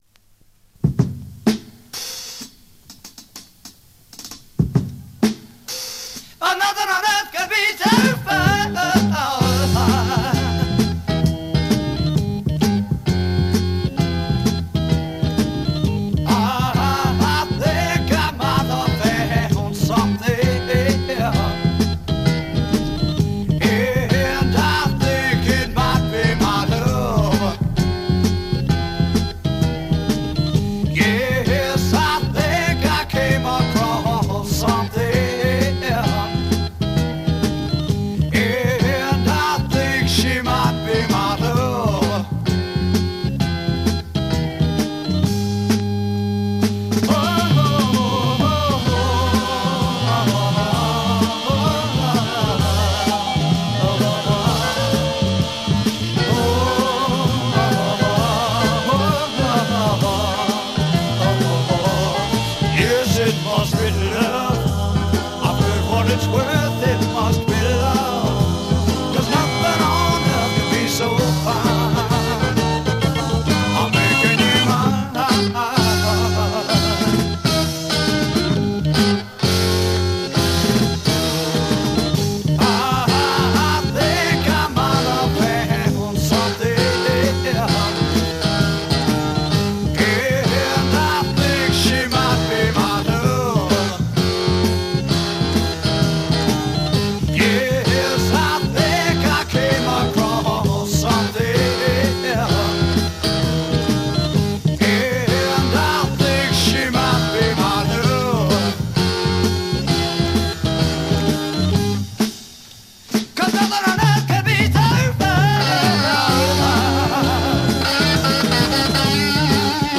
US Psych